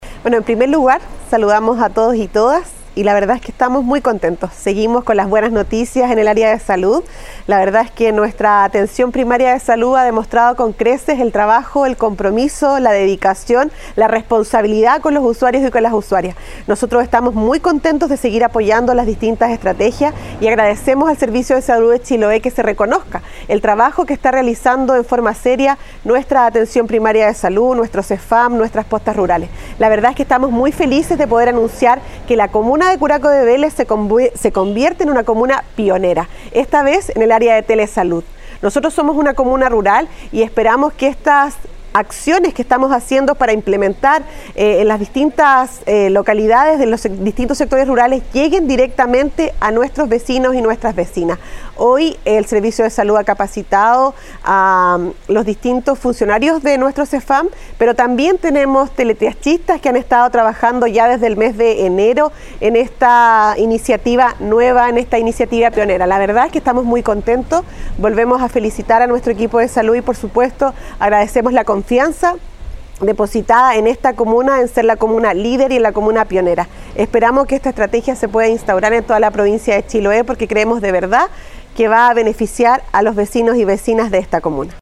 En tanto, la alcaldesa de Curaco de Vélez, Javiera Yáñez, expresó: